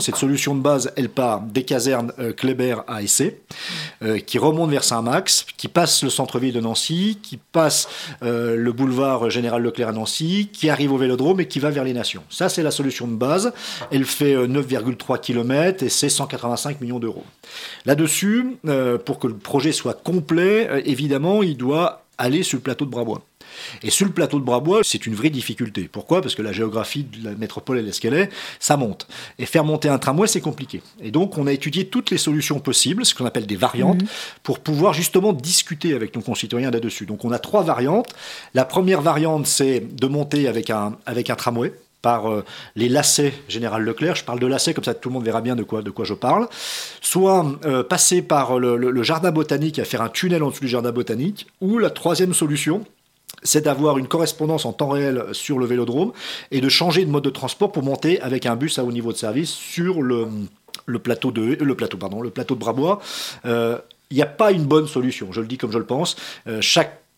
Christophe Choserot, Vice-Président du Grand Nancy en charge du dossier sur le renouvellement de la ligne 1, était ce lundi 15 janvier au micro de Radio Campus Lorraine. Des changements de véhicules et de trajets sont en discussion, une concertation est ouverte aux habitants de la métropole.